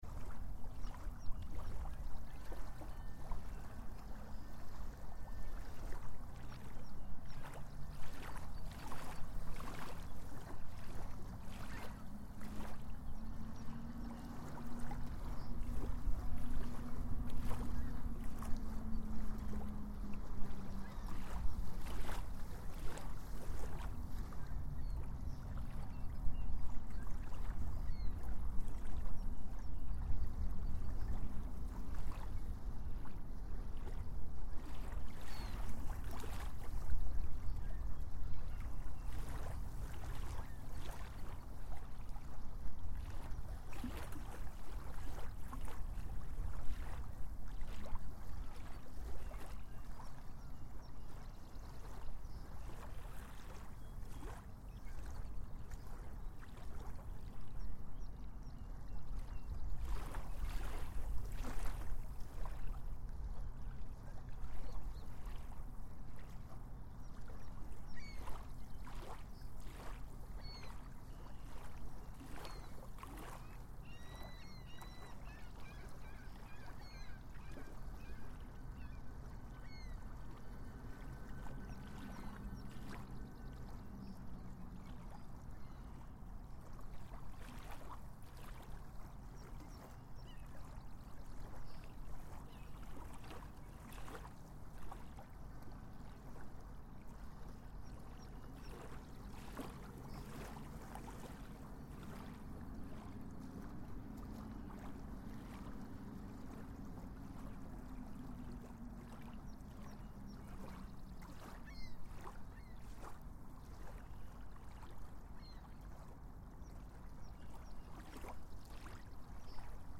In this audio recording, you can hear the gentle sound of small waves crashing onto the shore, accompanied by the sweet melody of birds singing and the distant chime of a train bell signalling its upcoming stop. The recording captures the beginning of spring as the sun rises, bringing in a new day.
Recorded in Hamilton, Canada